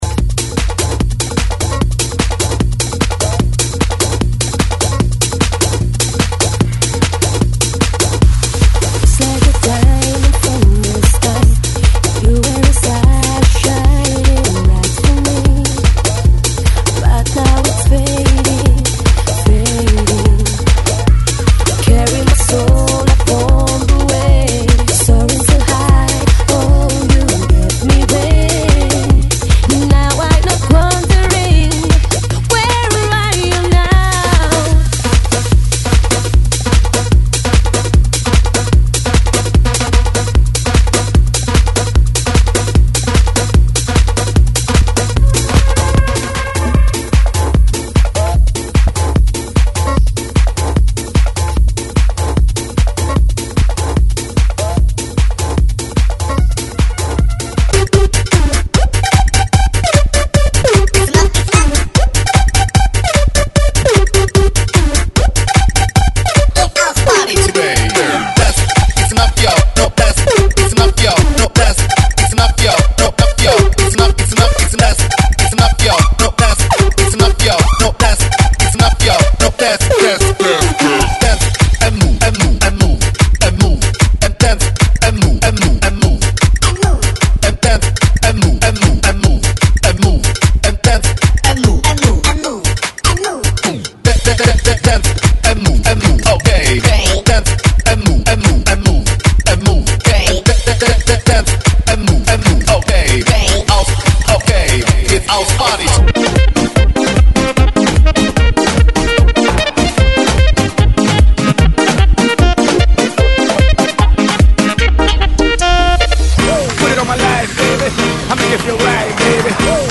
GENERO: SOUL LATINO
EJEMPLOS DE VELOCIDAD (BPM)
SOUL LATIN,